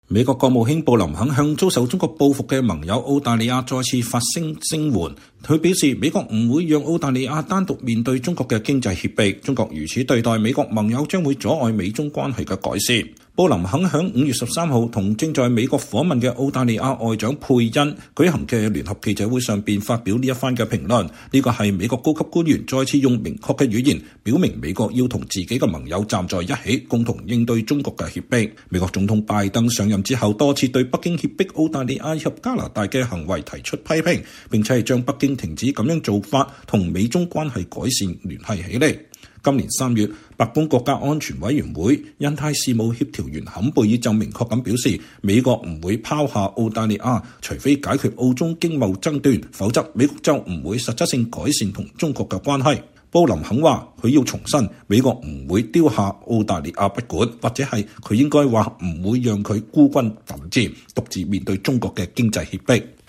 美國國務卿布林肯周四（5月13日）與正在美國訪問的澳大利亞外長佩恩舉行的聯合記者招待會。